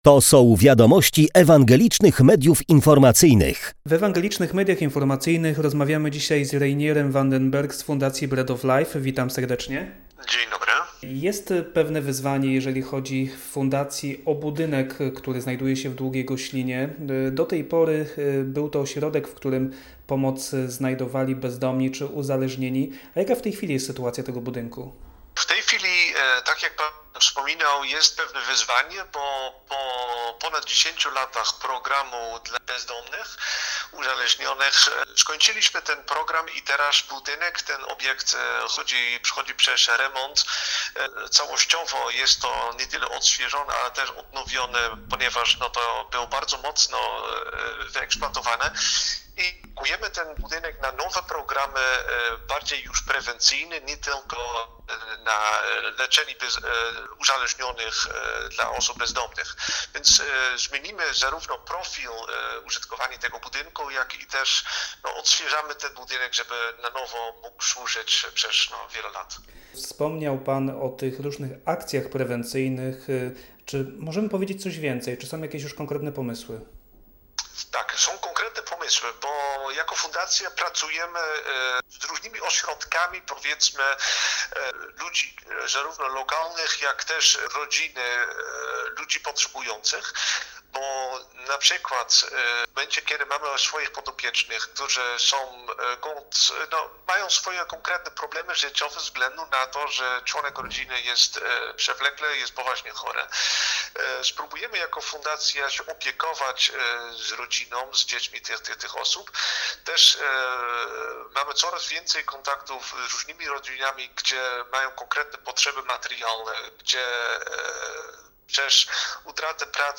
Zapraszamy na rozmowę